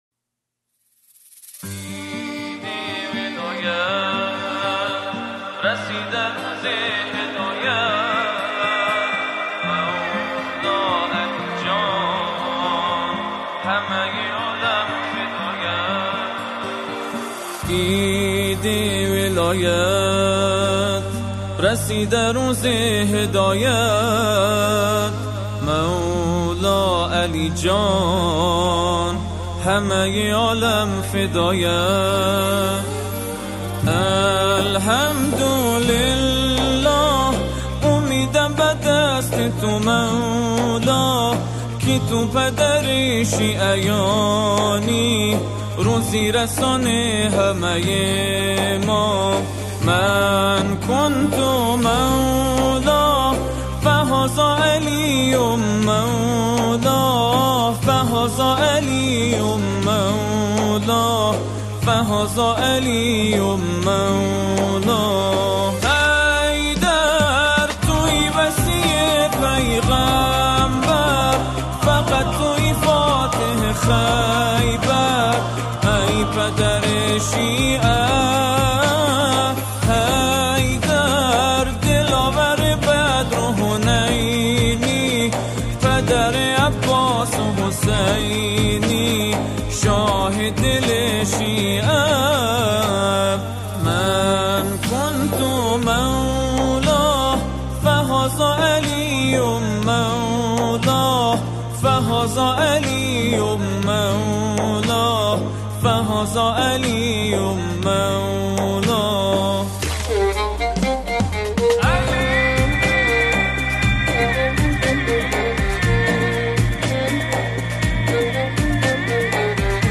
نماهنگ افغانستانی زیبا و دلنشین